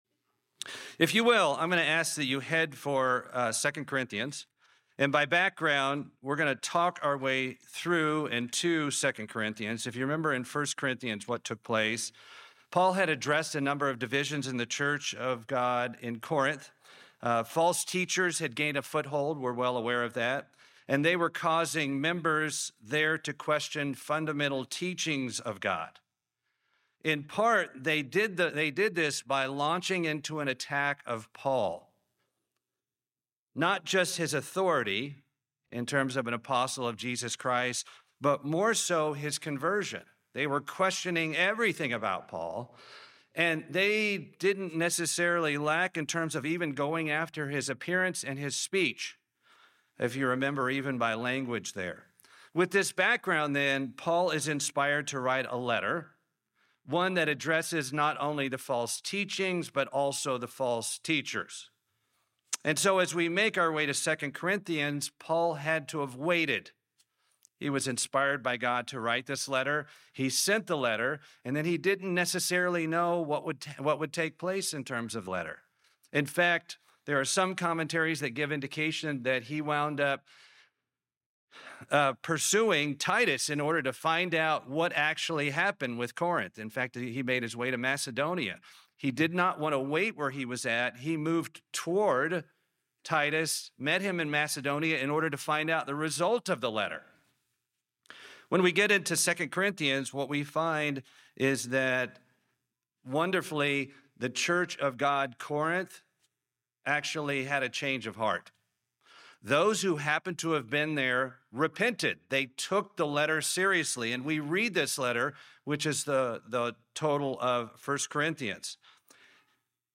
To begin this follow-up missive, he describes some characteristics of God, including that of comfort. This sermon focuses on this trait - which we as Christians need to understand.